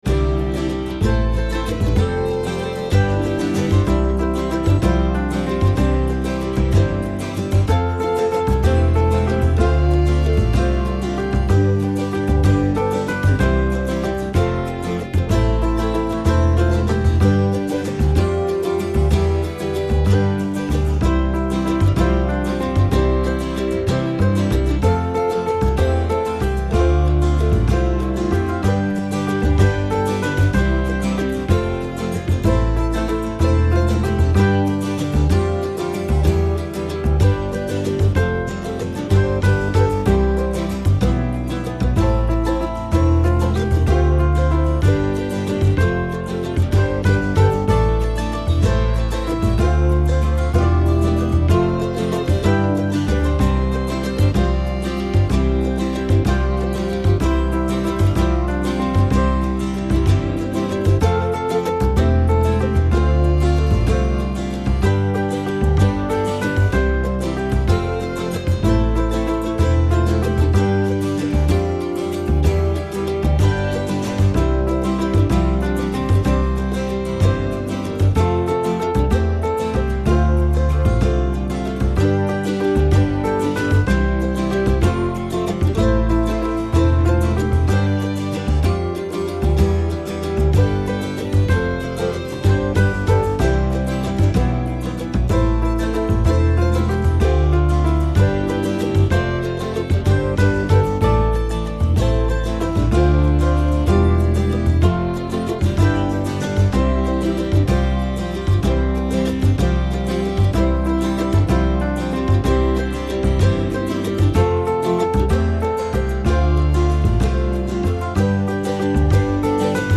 very singable and suited to guitar